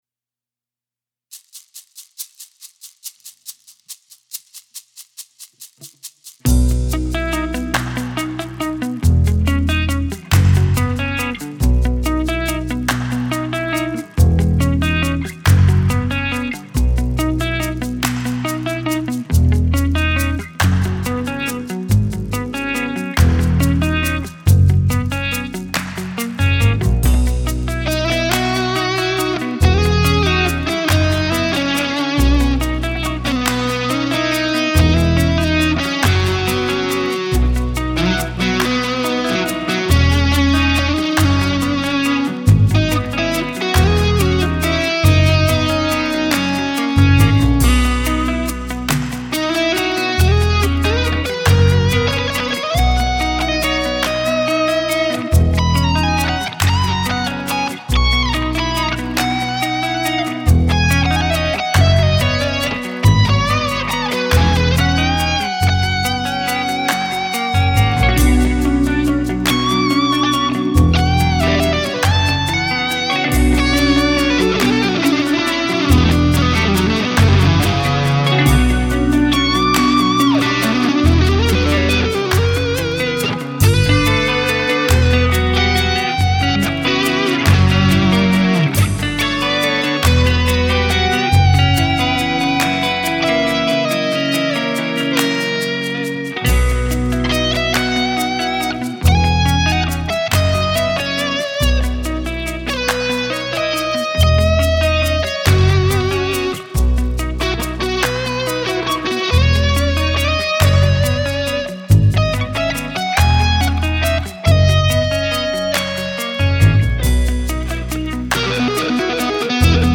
Framförs med bakgrundsmusik.